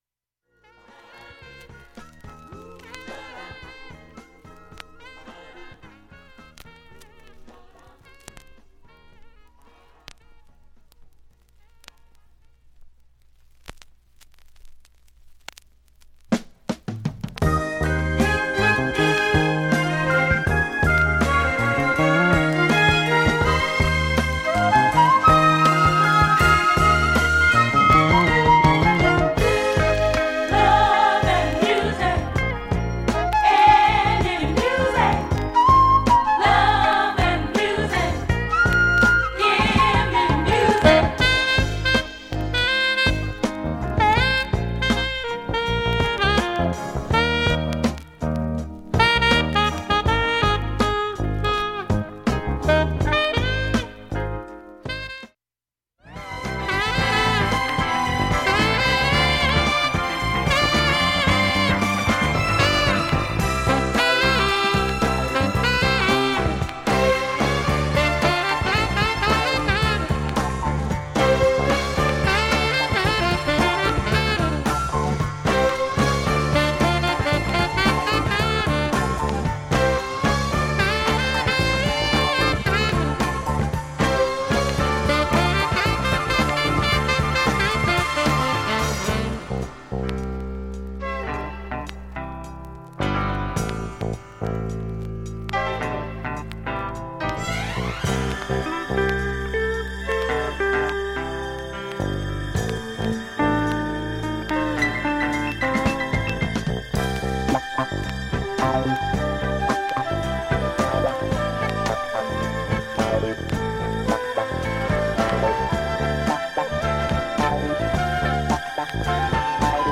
音質良好全曲試聴済み。
ほか７回までのかすかなプツが３箇所
３回までのかすかなプツが２箇所